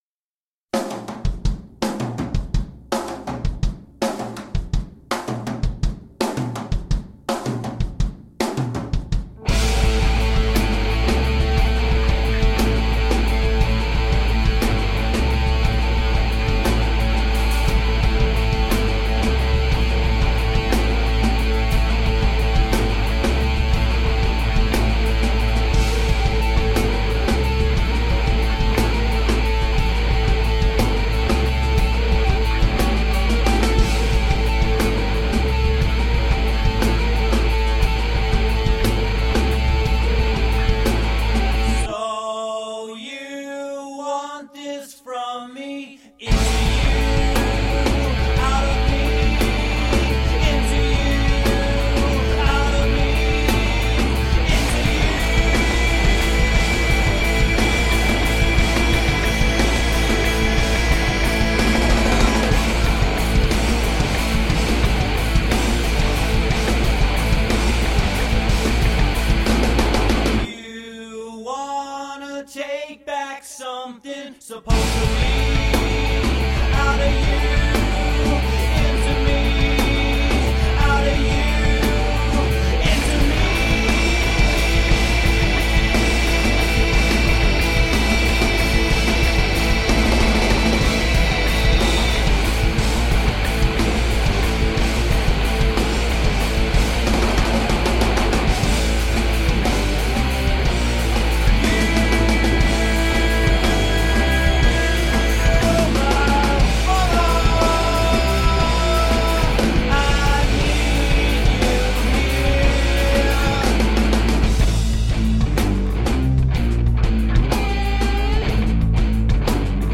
Heavy modern rock..
vaguely Celtic guitar line
Hard Rock, Punk, High Energy Rock and Roll